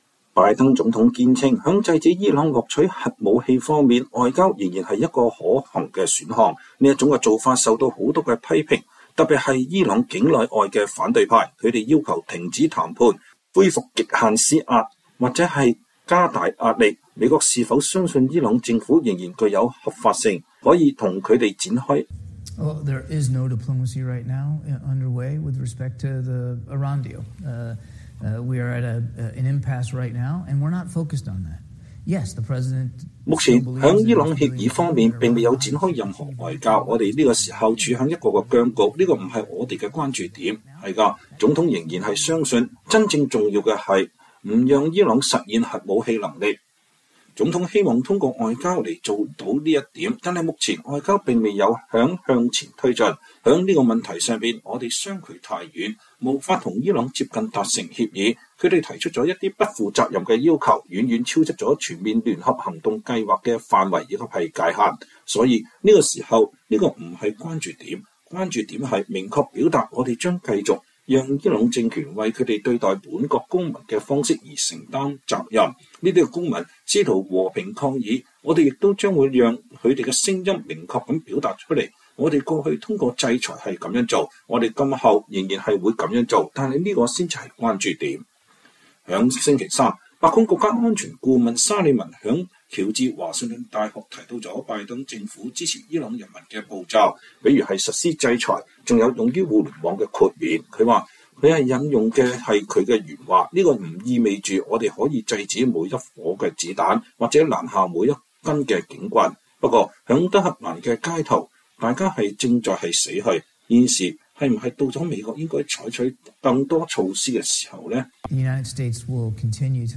美國之音波斯語部記者採訪了美國國家安全委員會發言人約翰·科比(John Kirby) ，談論了伊朗抗議、伊朗核協議外交、烏克蘭戰爭最新局勢、石油減產決定和拜登政府與沙特阿拉伯的關係，以及美中關係與新推出的美國國家安全戰略，還提到了北京在中共二十大前夕出現的反對習近平的標語等。